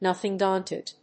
nóthing dáunted